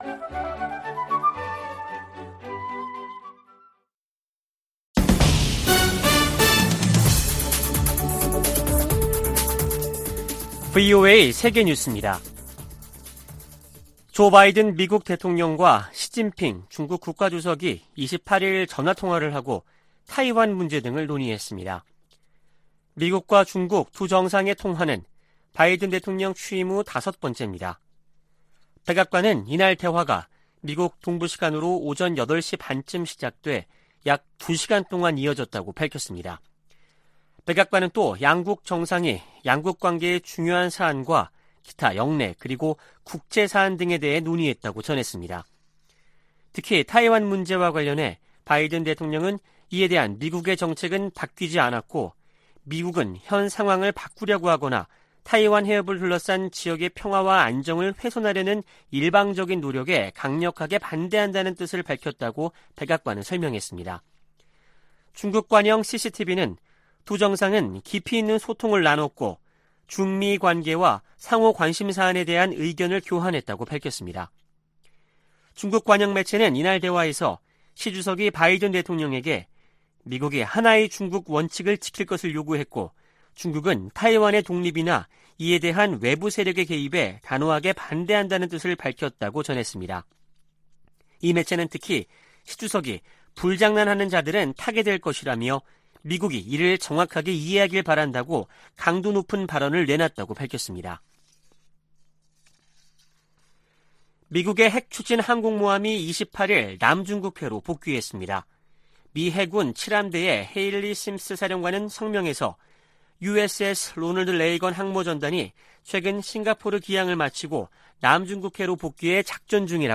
VOA 한국어 아침 뉴스 프로그램 '워싱턴 뉴스 광장' 2022년 7월 29일 방송입니다. 미국은 북한 정부 연계 해킹조직 관련 정보에 포상금을 두배로 올려 최대 1천만 달러를 지급하기로 했습니다. 북한의 핵 공격 가능성이 예전에는 이론적인 수준이었지만 이제는 현실이 됐다고 척 헤이글 전 미 국방장관이 평가했습니다. 김정은 북한 국무위원장이 미국과 한국을 강력 비난하고, 미국과의 군사적 충돌에 철저히 준비할 것을 다짐했다고 관영 매체들이 보도했습니다.